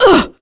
Maya-Vox_Damage1.wav